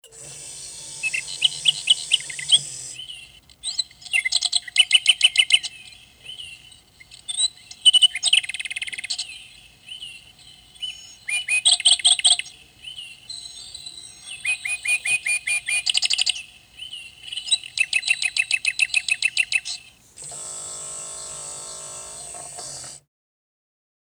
Zur vollen Stunde fährt der Vogel hinaus und Vogelgezwitscher ♫ ertönt
Vogelgezwitscher.MP3